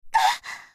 slayer_f_voc_hit_c.mp3